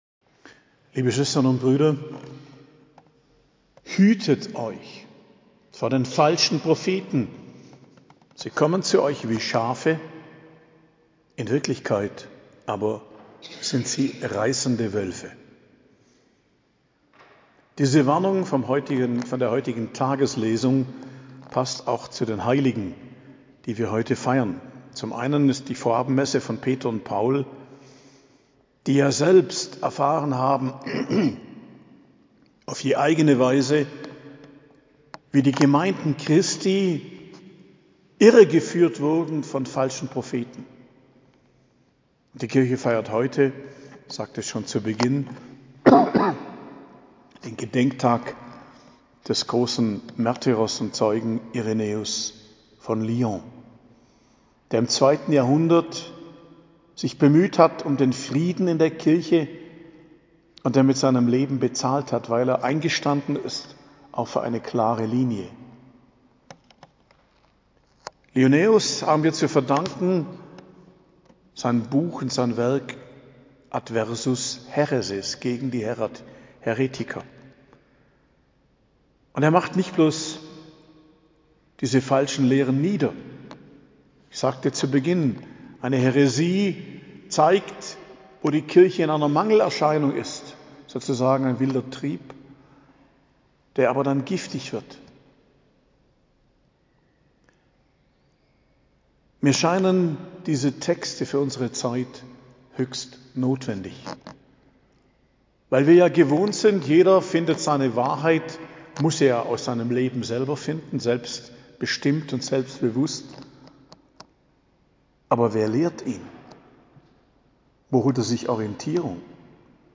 Predigt am Mittwoch der 12. Woche i.J., 28.06.2023